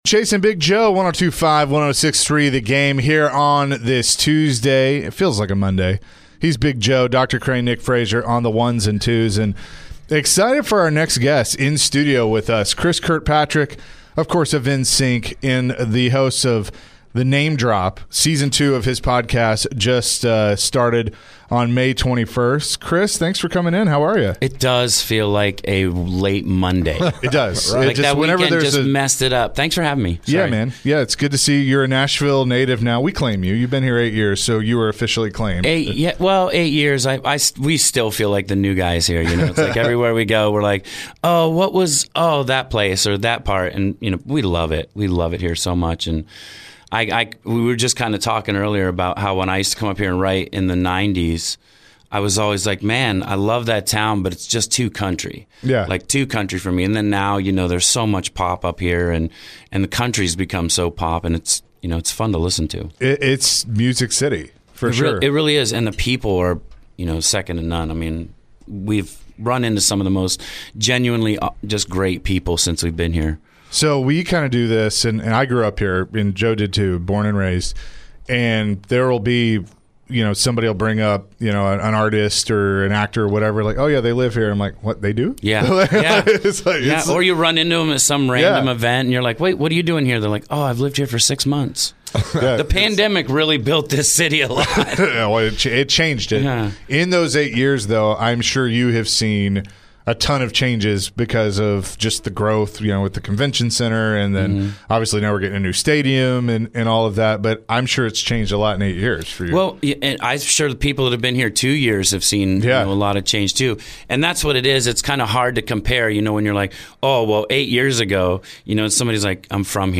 NSYNC’s Chris Kirkpatrick joined the show in the studio. Chris shared some laughs and great stories.